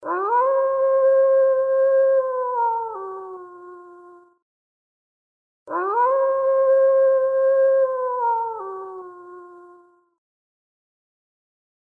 Волчий вой